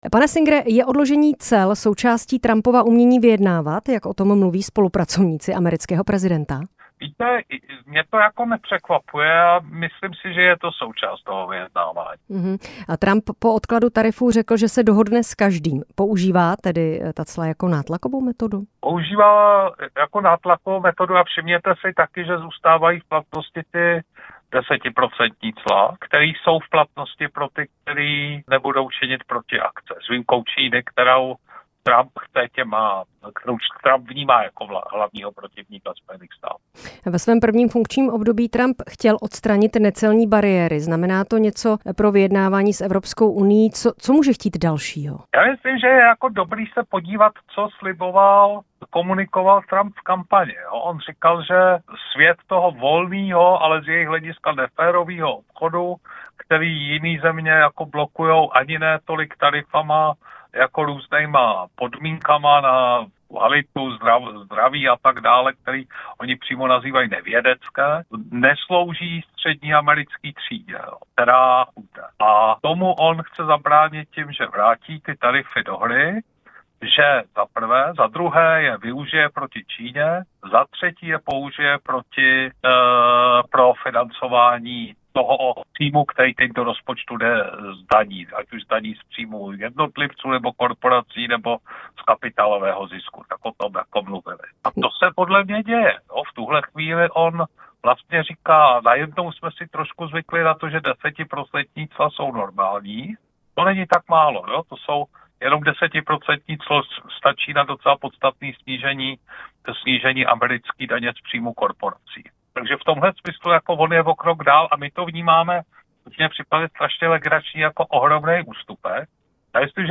Rozhovor s Miroslavem Singerem